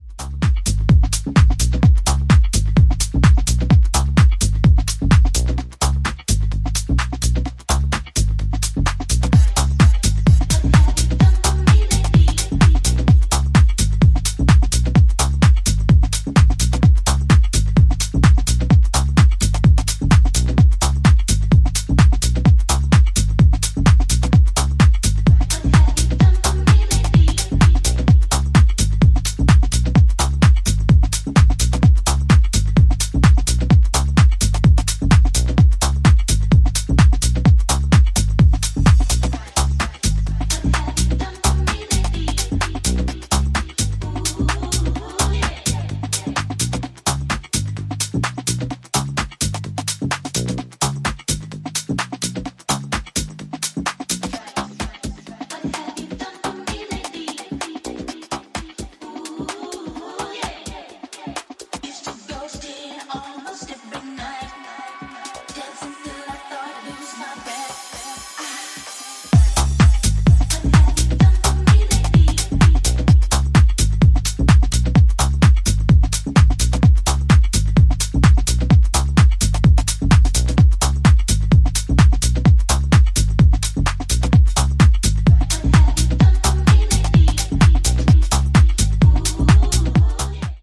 ジャンル(スタイル) TECH HOUSE / HOUSE